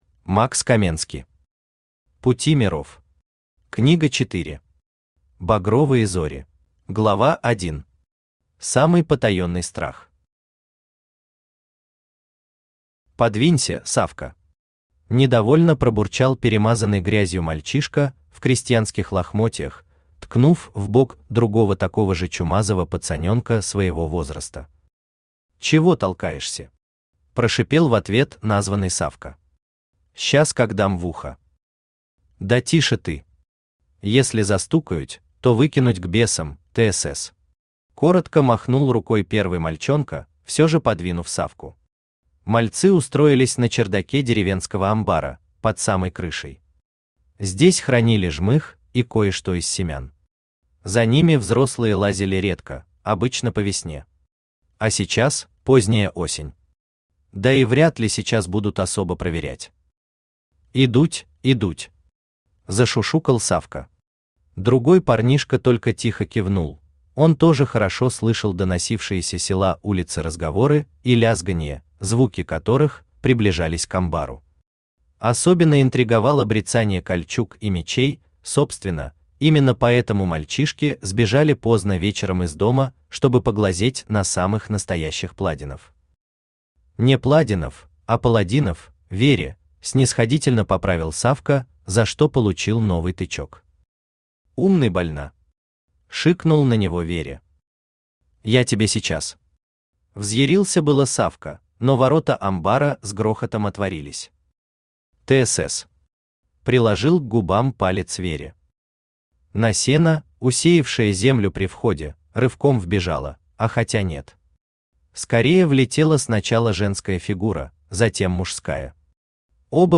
Aудиокнига Пути миров. Книга 4. Багровые зори Автор Макс Каменски Читает аудиокнигу Авточтец ЛитРес.